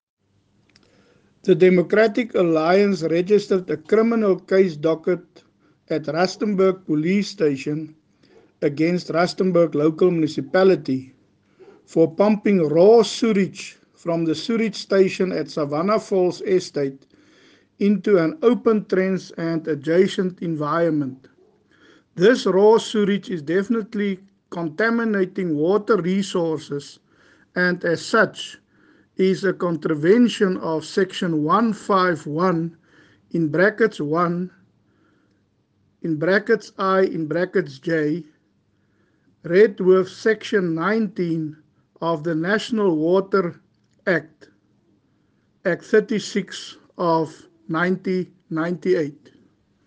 Issued by Cllr Gert du Plessis – DA Caucus Leader: Rustenburg Local Municipality
Note to Editors: Please find the attached soundbite in